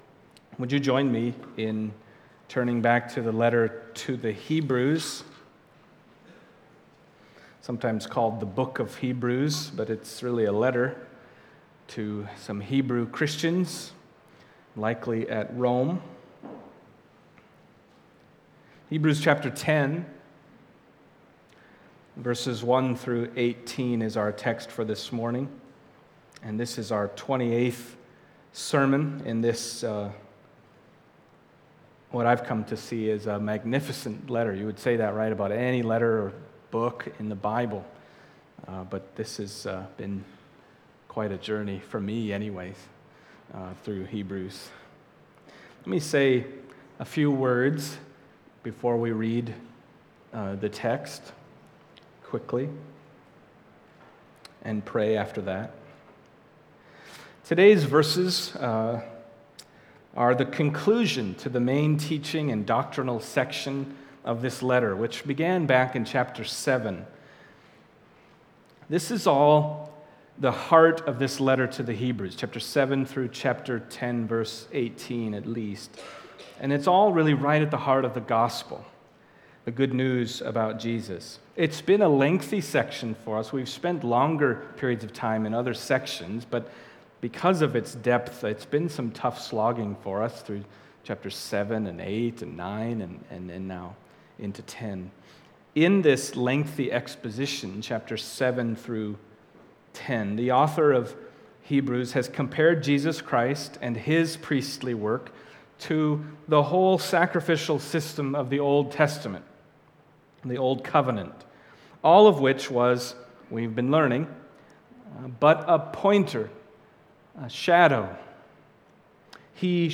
Passage: Hebrews 10:1-18 Service Type: Sunday Morning Hebrews 10:1-18 « Once for All at the End of the Ages How Should We Then Live?